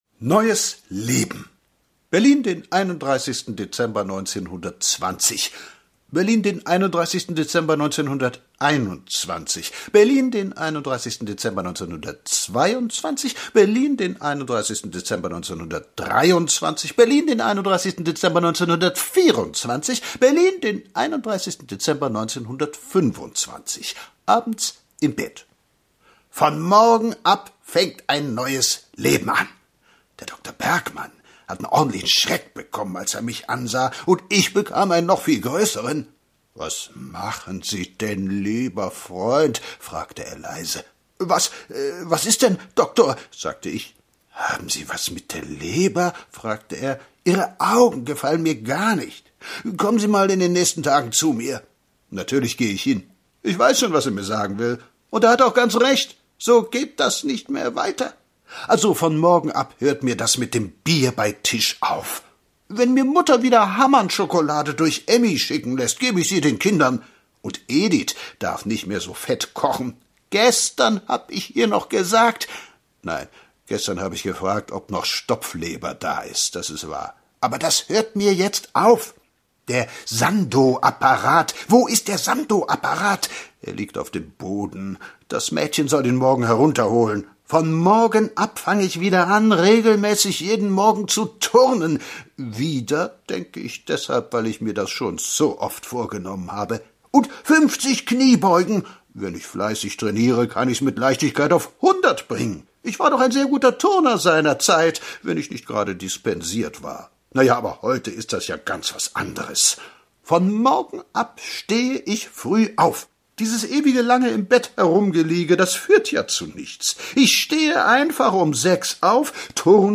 Gelesen